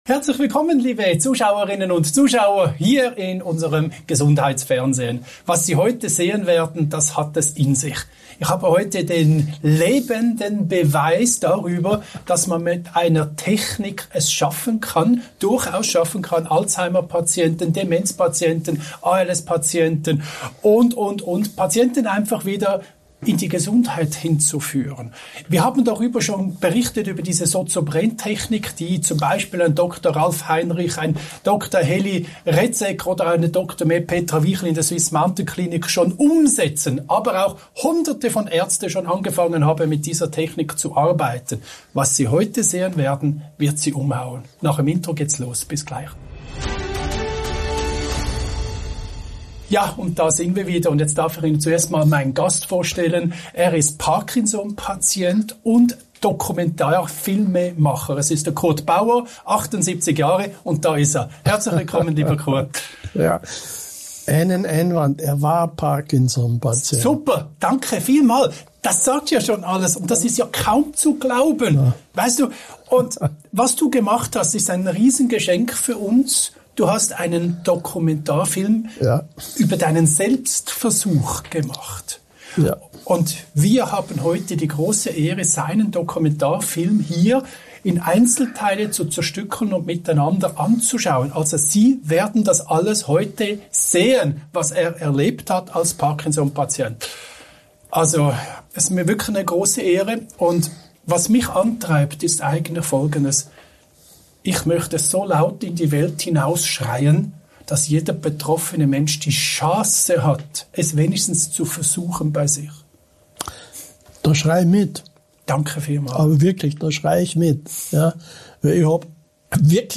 Ein Gespräch, das Mut macht – und neue Horizonte öffnet